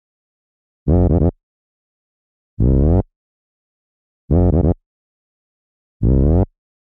太阳反转卡西欧CZ Psy Bass 1 Bb Maj 140 BPM
描述：这是用卡西欧CZ101(Cosmo)合成器创作的一套psy trance低音线中的第一条。
标签： 140 bpm Trance Loops Bass Synth Loops 590.82 KB wav Key : A
声道单声道